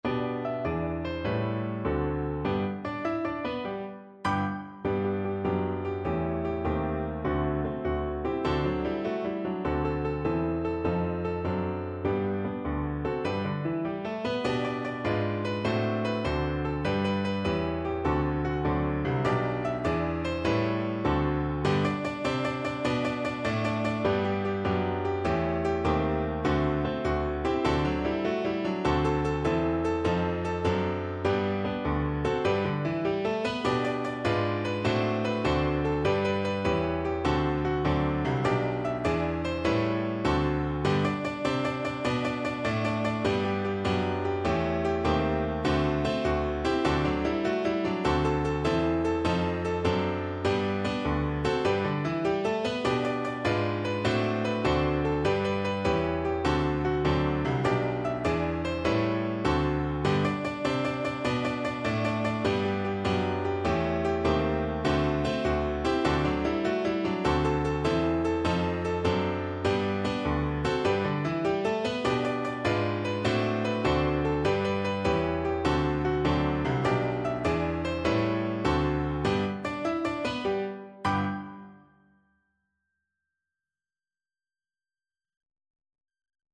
6/8 (View more 6/8 Music)
Traditional (View more Traditional Voice Music)